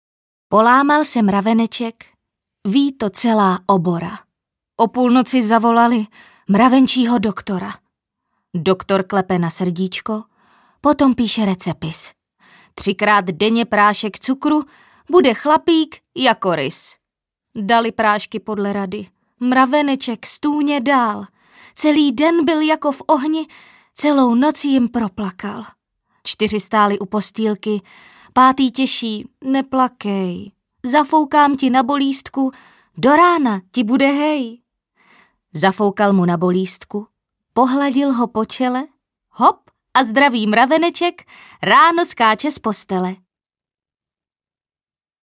300 nejznámějších dětských říkadel audiokniha
Ukázka z knihy
Naše nahrávka obsahuje nejznámější říkadla o zvířátkách, o jménech či o ročních obdobích doplněná národními písničkami.